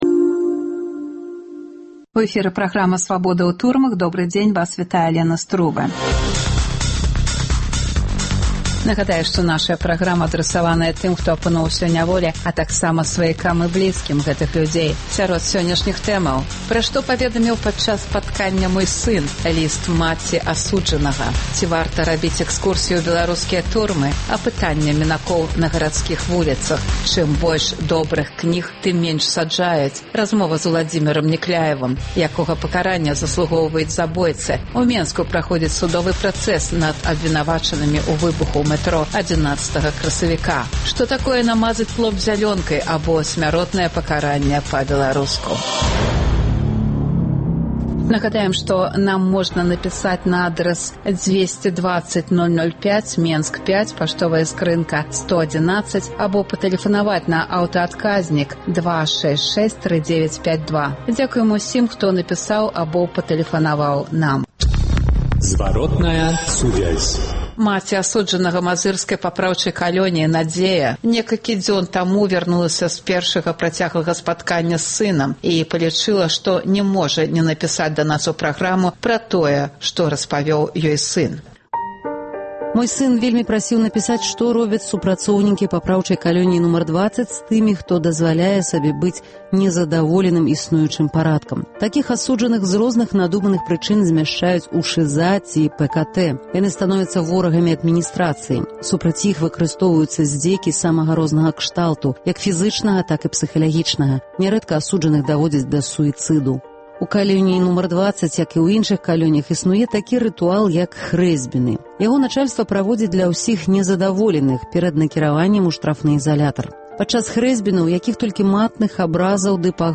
Апытаньне мінакоў на гарадзкіх вуліцах.
Гутарка з Уладзімерам Някляевым.